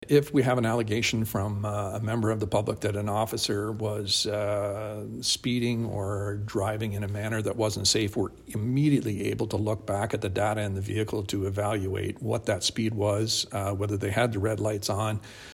Police Chief Mike Callaghan says the technology is very useful.